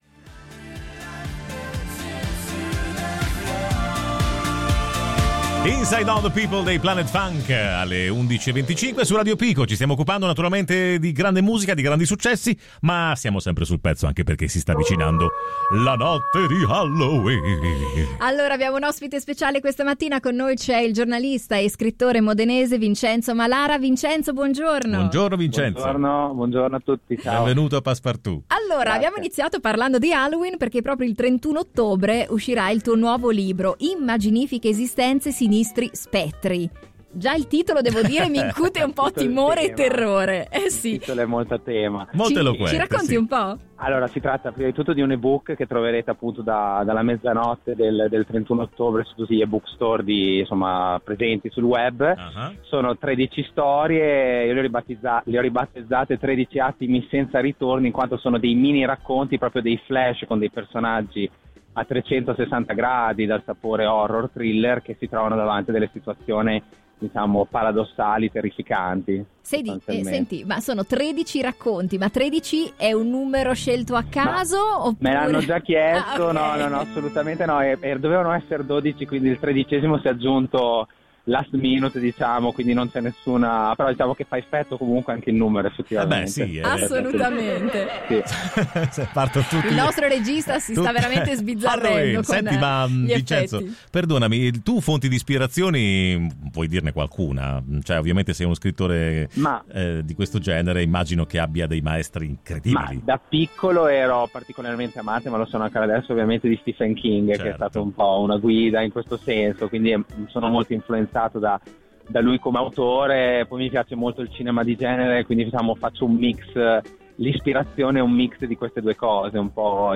Ecco la nostra intervista